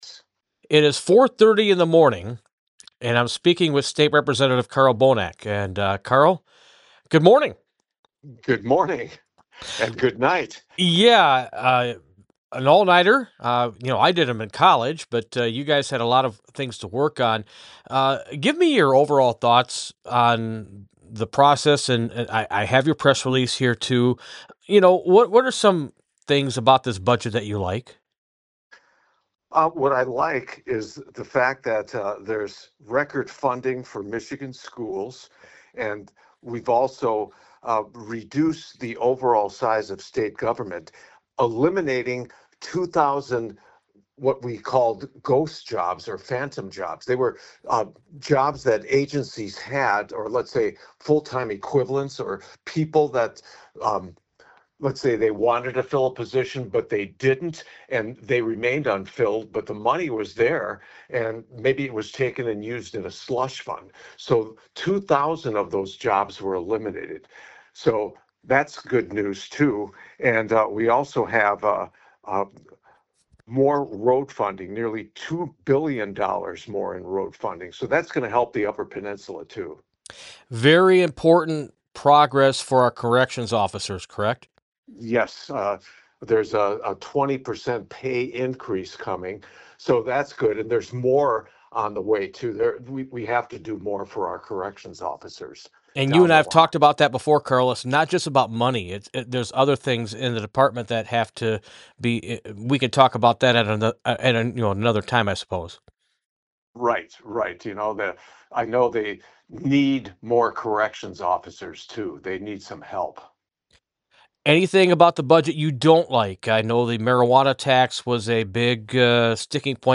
LATE-NIGHT INTERVIEW